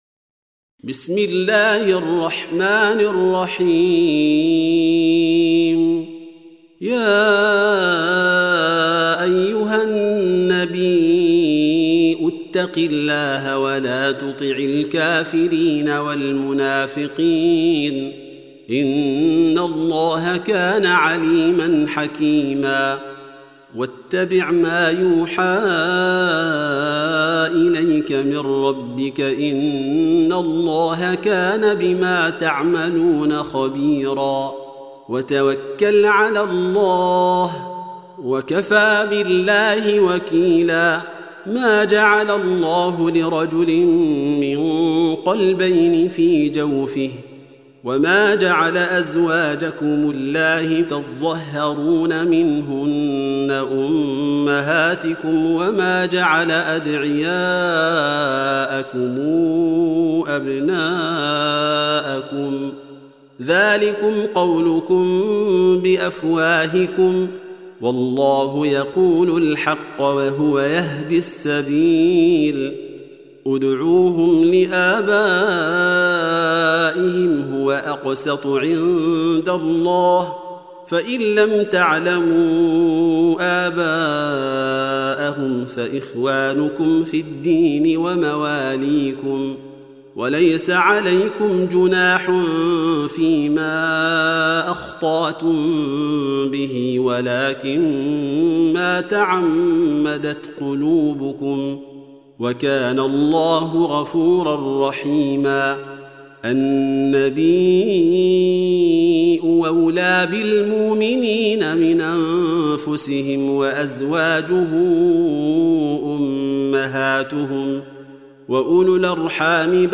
Surah Sequence تتابع السورة Download Surah حمّل السورة Reciting Murattalah Audio for 33. Surah Al�Ahz�b سورة الأحزاب N.B *Surah Includes Al-Basmalah Reciters Sequents تتابع التلاوات Reciters Repeats تكرار التلاوات